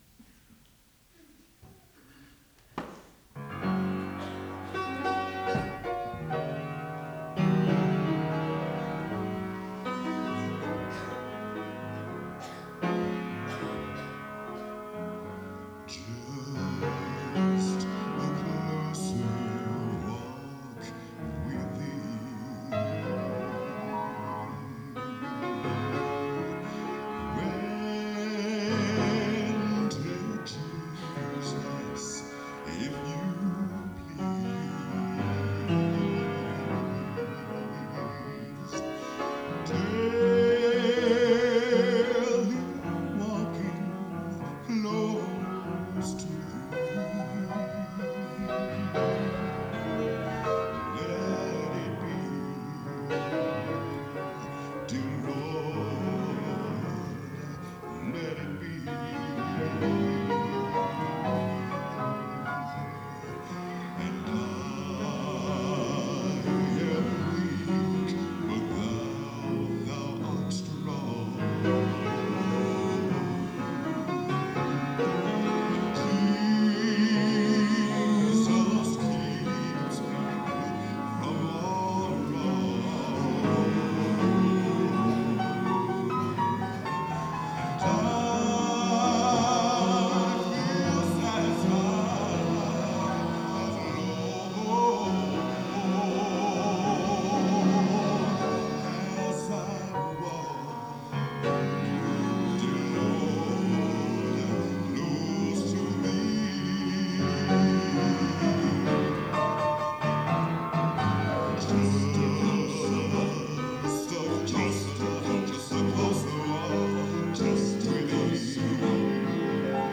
Genre: Gospel | Type: Featuring Hall of Famer |Specialty